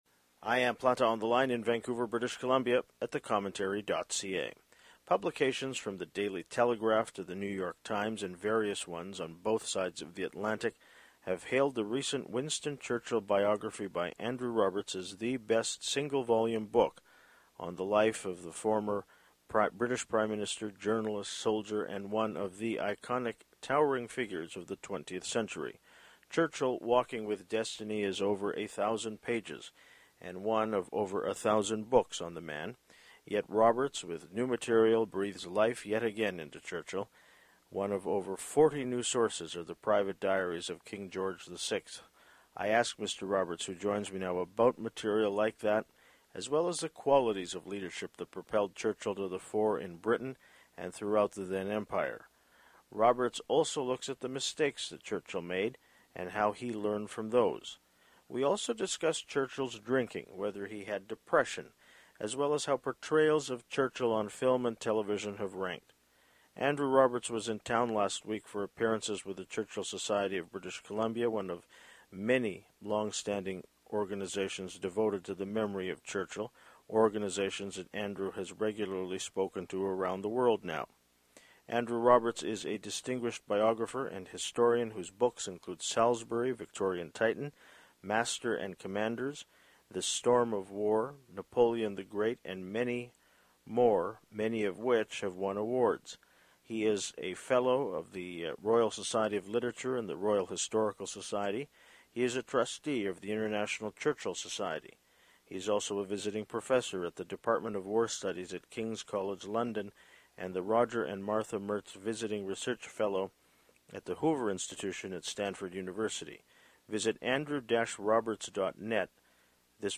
I ask Mr. Roberts, who joins me now, about material like that, as well as the qualities of leadership that propelled Churchill to the fore in Britain, and throughout the then Empire. Roberts also looks at the mistakes that Churchill made, and how he learned from those. We also discuss Churchill’s drinking, whether he had depression, as well as how portrayals of Churchill on film and television have ranked.